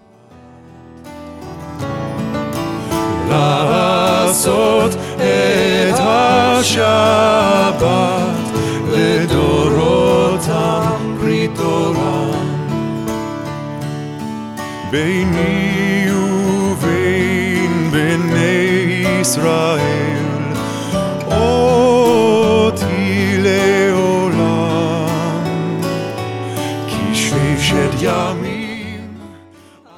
hooky intellectual rock
gorgeous, introspective liturgical modern standards